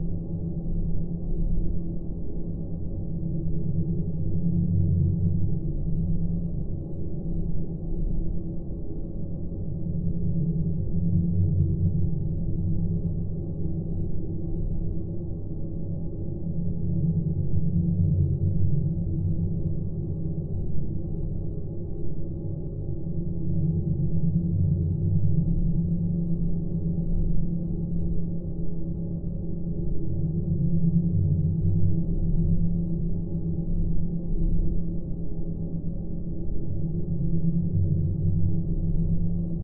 dc0f4c9042 Divergent / mods / Soundscape Overhaul / gamedata / sounds / ambient / soundscape / background / underground_4.ogg 1.0 MiB (Stored with Git LFS) Raw History
underground_4.ogg